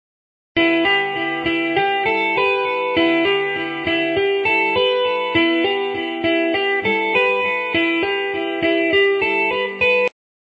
For this reason you will find at the beginning and at the end of each sample 0.5 seconds of silence.
The guitar bits&pieces:
tapping - very stick-y! (61kB)
tapped1.wav